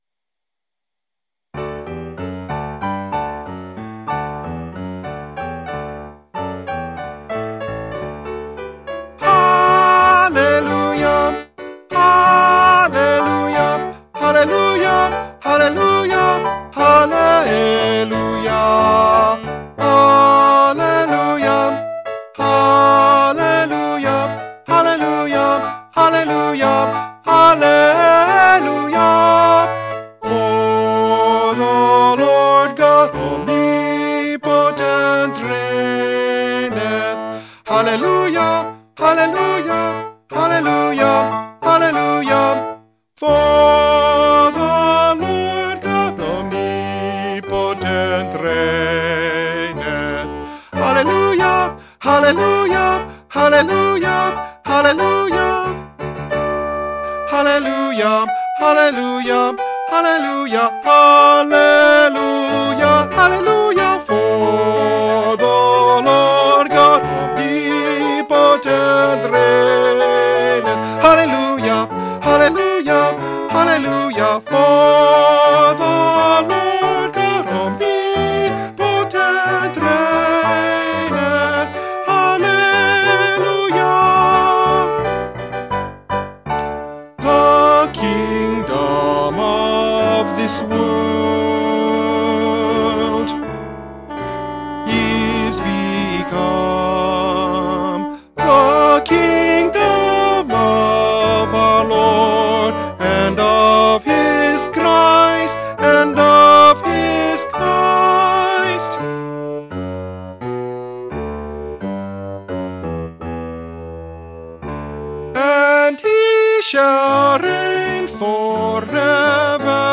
Tenor   Instrumental | Downloadable